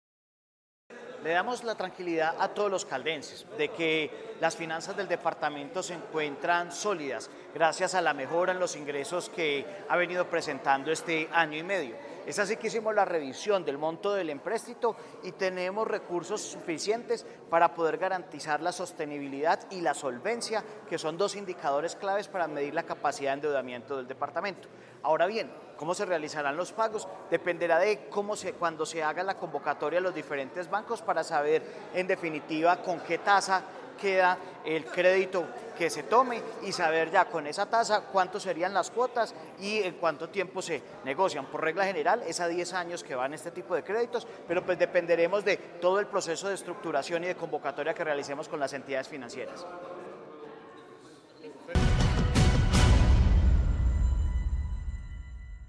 Secretario de Hacienda, Jhon Alexander Alzate Quiceno.